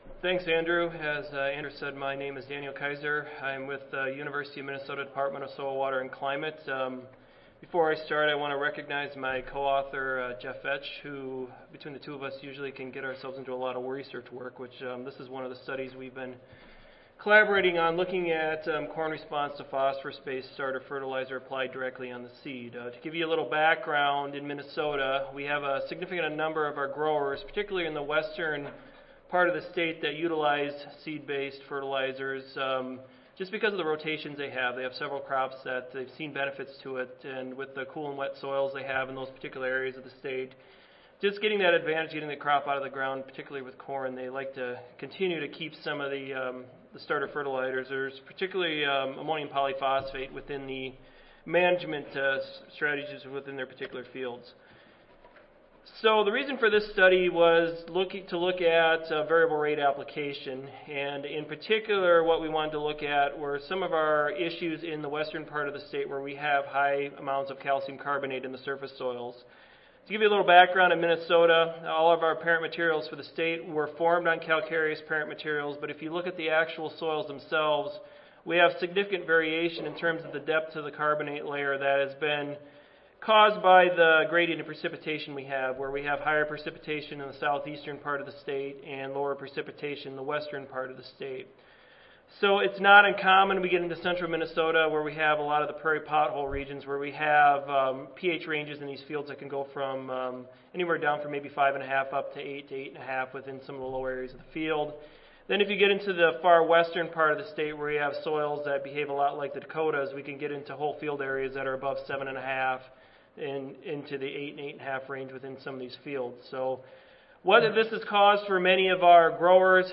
Session: Phosphorus Science & Management (ASA, CSSA and SSSA International Annual Meetings (2015))
University of Minnesota Audio File Recorded Presentation